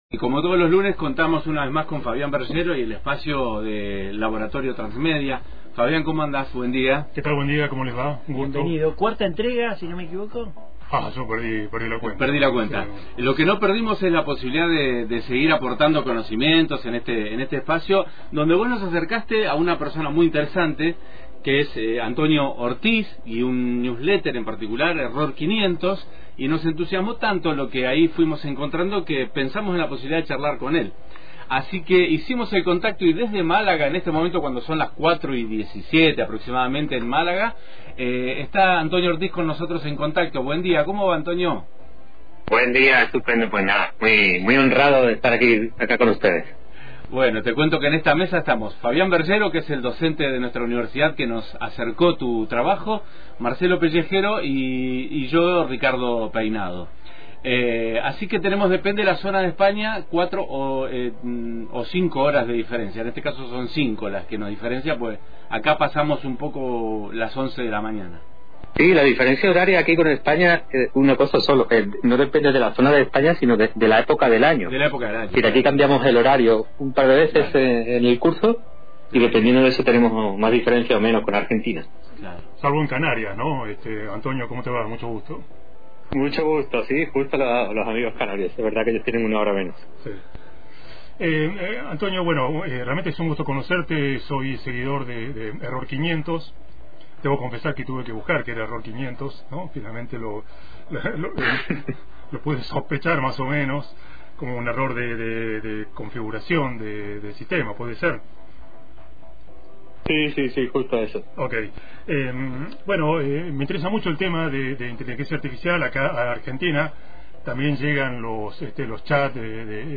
«Media Lab» – Entrevista